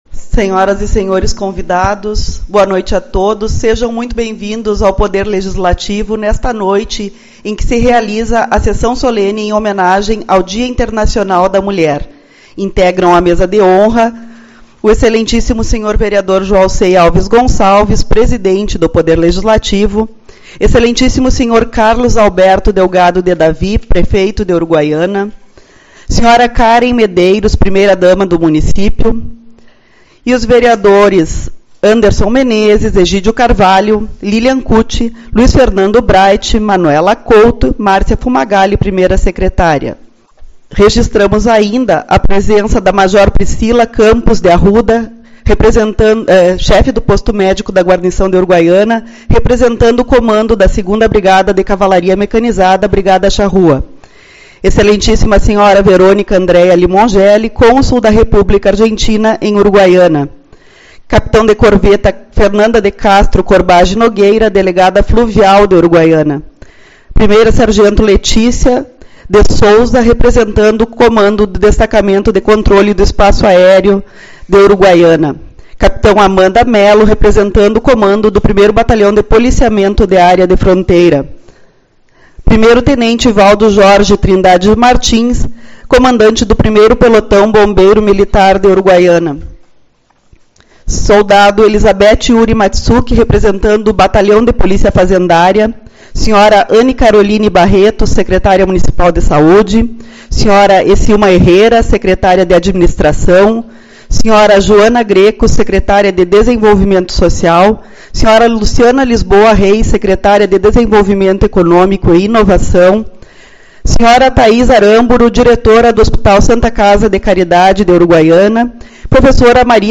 06/03 - Sessão Solene-Dia Internacional da Mulher